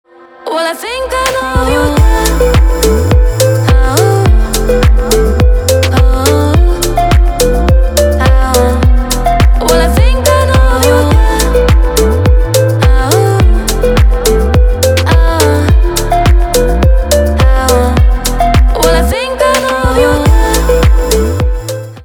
танцевальные
романтические
битовые